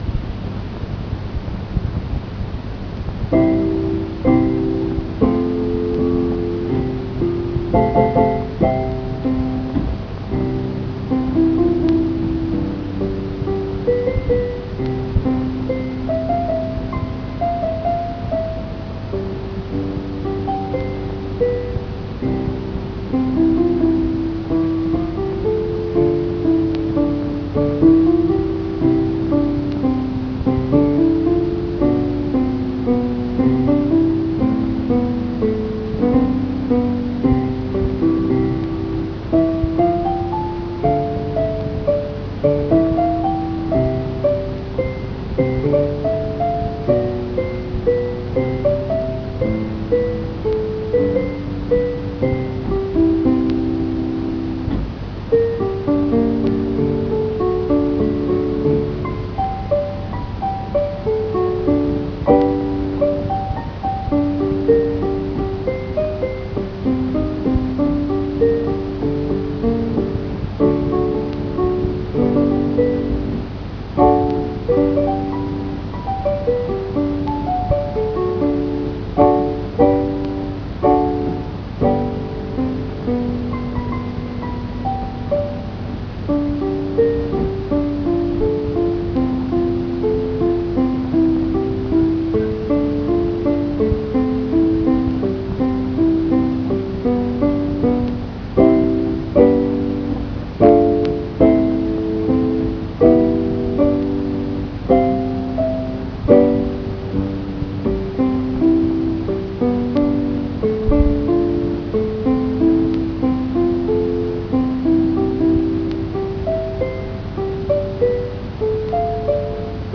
me mucking about on a keyboard